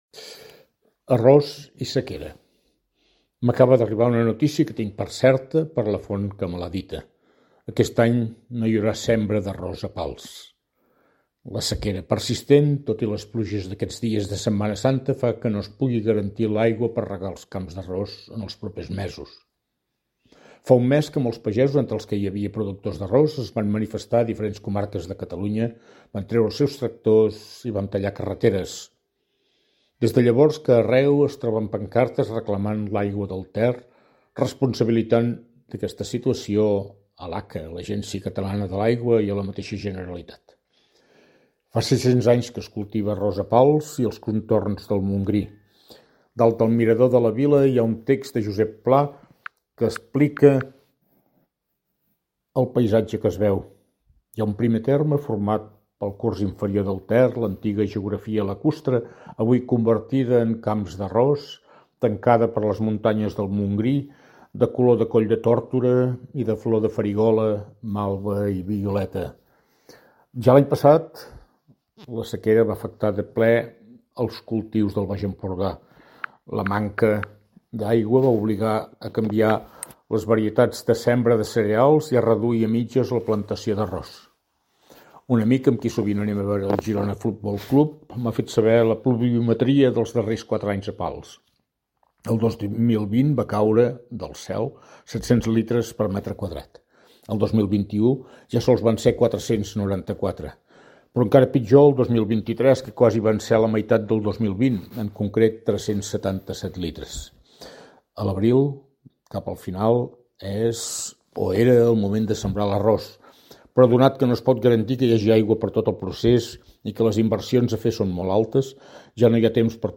Opinió